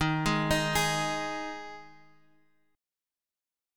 Ab5/Eb Chord
Listen to Ab5/Eb strummed